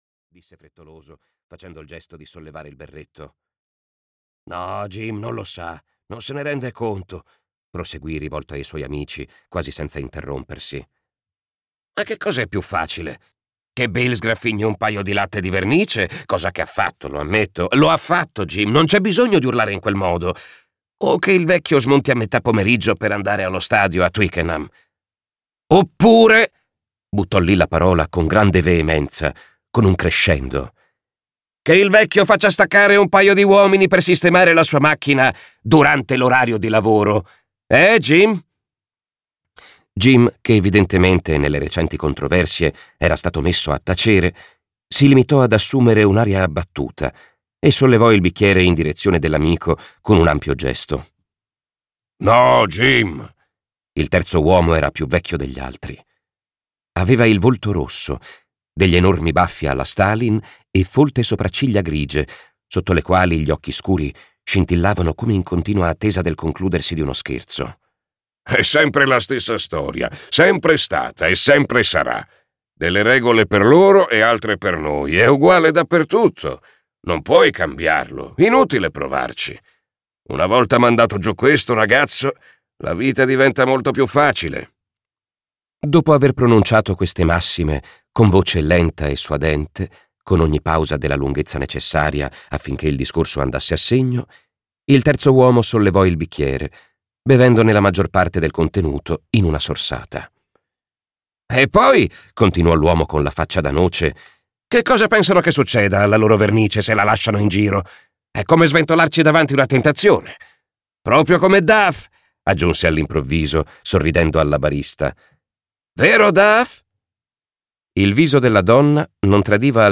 Un mistero di Natale" di Mary Kelly - Audiolibro digitale - AUDIOLIBRI LIQUIDI - Il Libraio